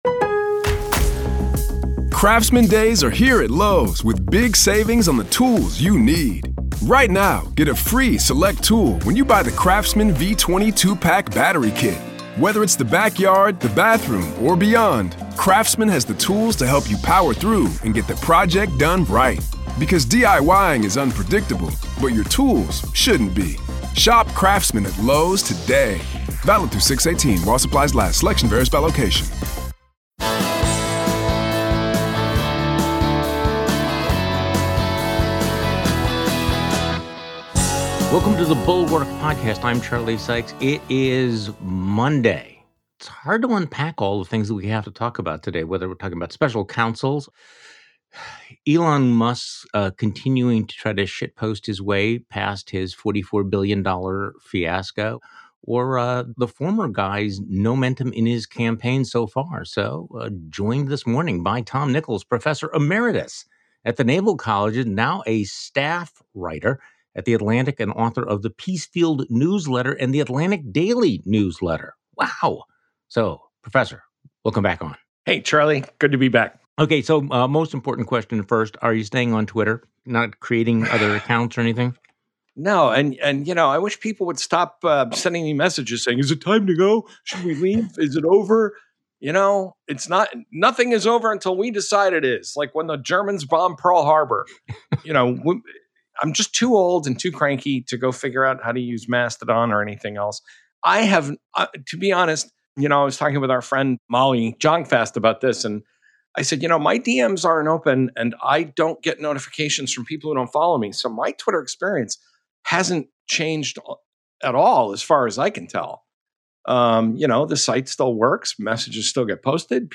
Once again, the anti-anti-Trumpers are standing back and hoping Trump will run out of tricks, instead of calling him out as an existential threat to democracy. Plus, will McCarthy really get the gavel — and how long could he possibly hold it? Tom Nichols joins Charlie Sykes today.